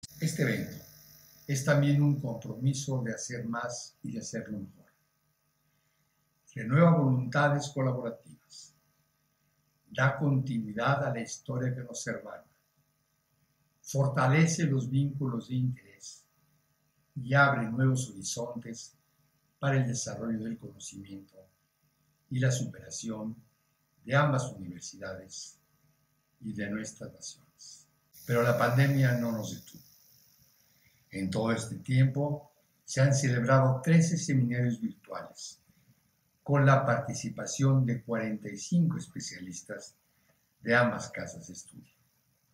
En la inauguración del encuentro realizado en modalidad mixta (presencial en la Sorbona y virtual en el resto del orbe), por medio de un videomensaje, el rector dijo que esa institución francesa es paradigmática en la educación superior en el mundo.